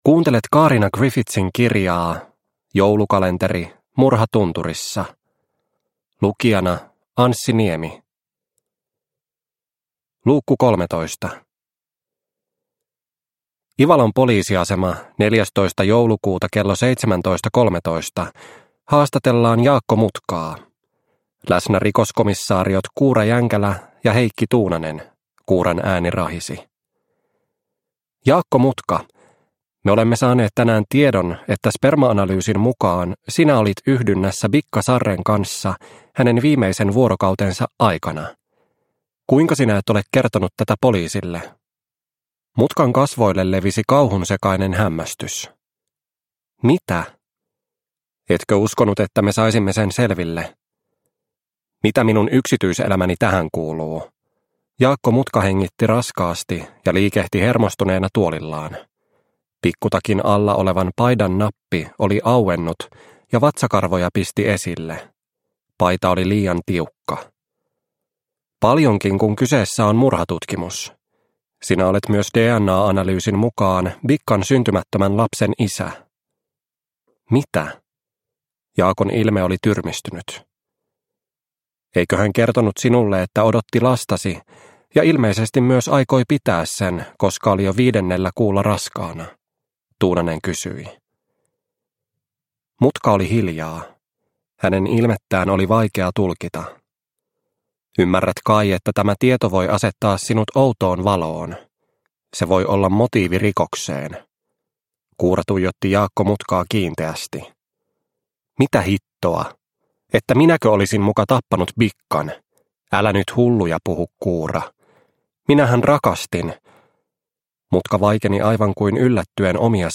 Murha tunturissa - Osa 13 – Ljudbok – Laddas ner